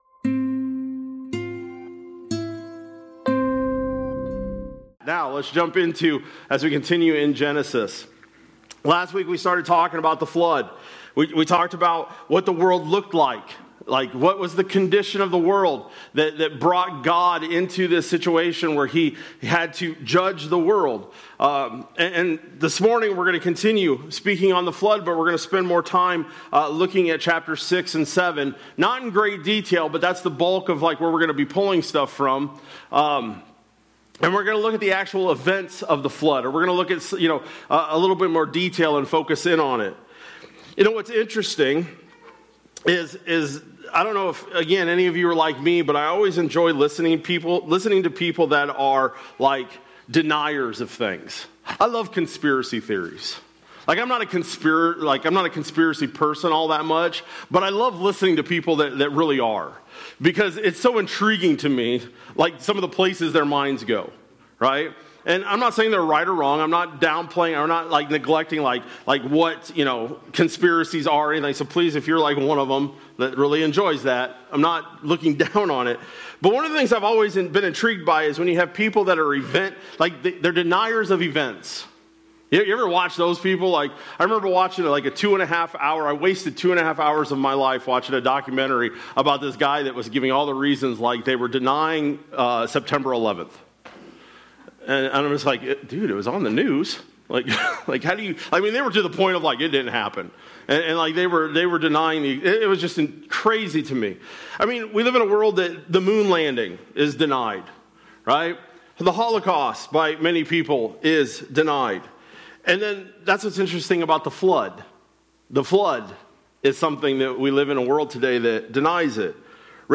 March-15-26-Sermon-Audio.mp3